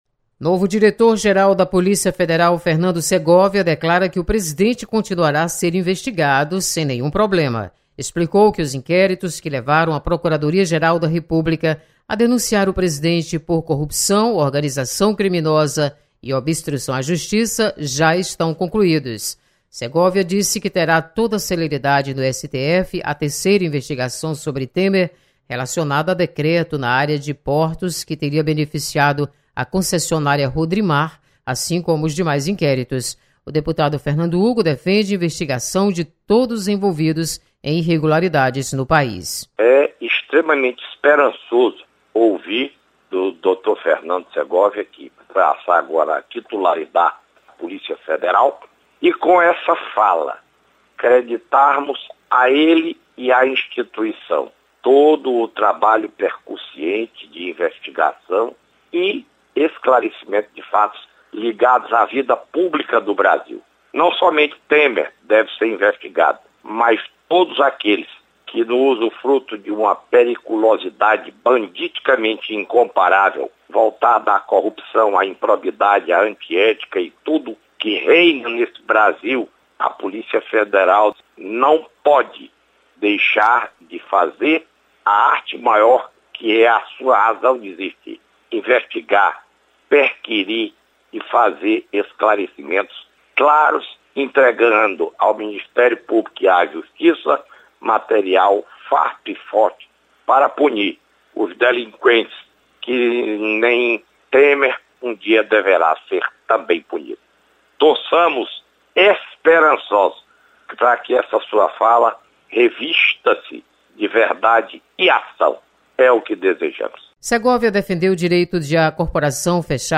Deputados comentam sobre declarações do novo diretor da Polícia Federal. (3'21")